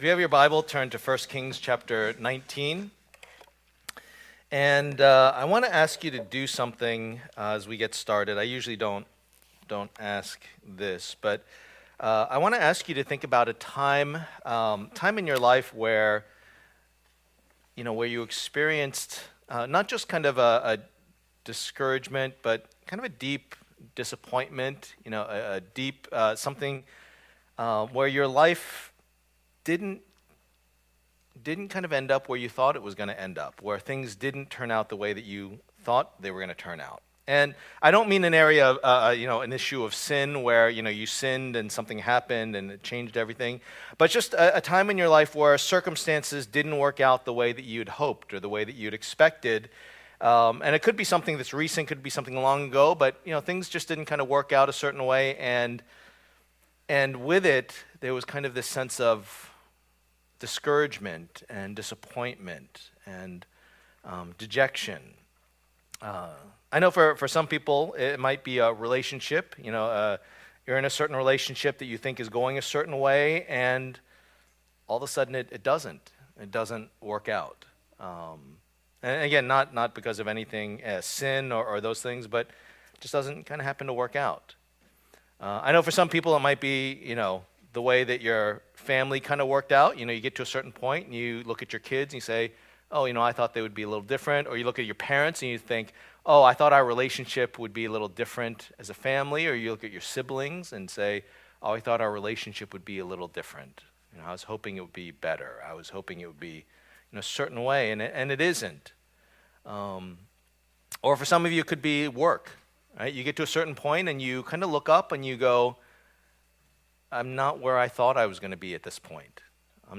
Fighting Against the Idols Passage: 1 Kings 19:1-18 Service Type: Lord's Day %todo_render% « The Lord